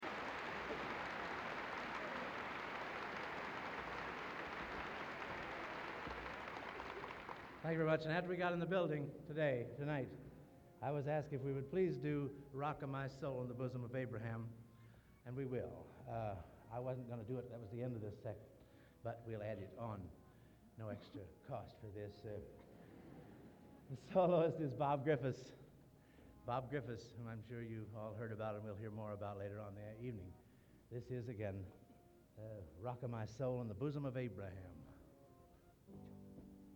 Collection: Hammond Concert, 1960
Genre: | Type: Director intros, emceeing